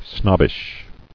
[snob·bish]